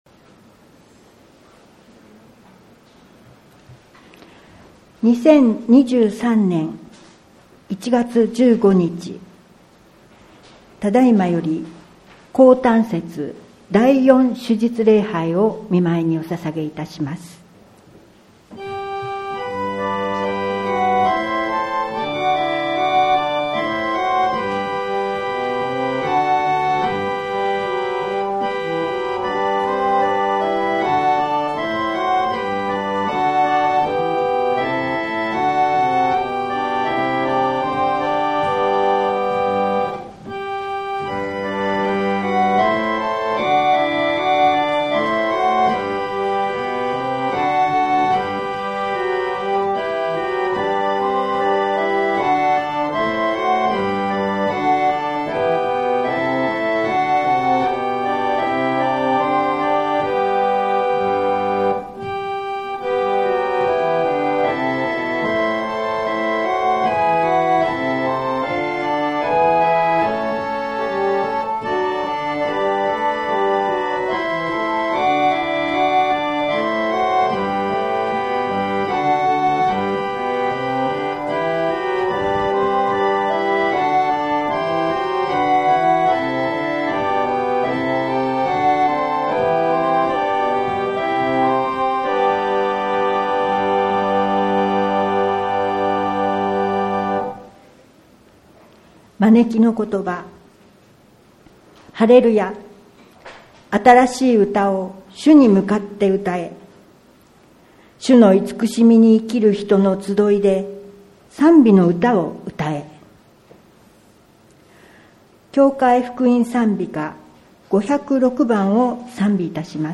2022年12月18日礼拝音源配信はこちら